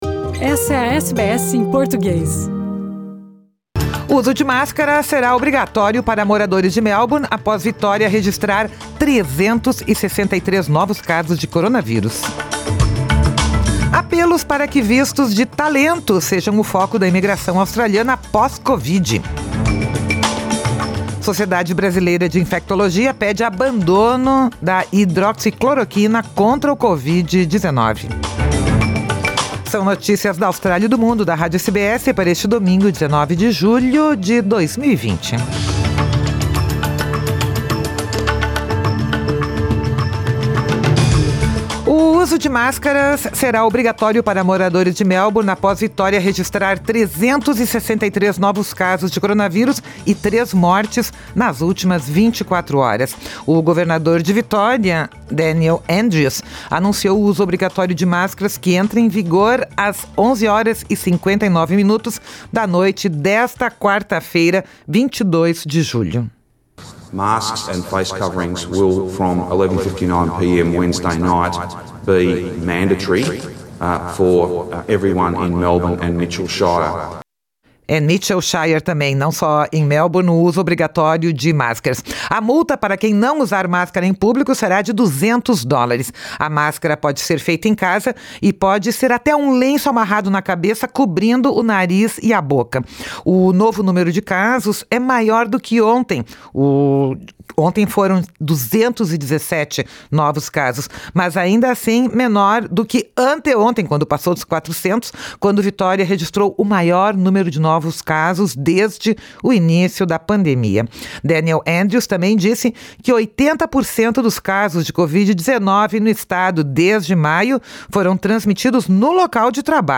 Máscaras obrigatórias em Melbourne, Sociedade Brasileira de Infectologia pede abandono de hidroxicloroquina contra Covid-19 e apelos para que vistos de talento sejam o foco da imigração australiana pós-Covid - destaques do noticiário deste domingo da Rádio SBS em Português.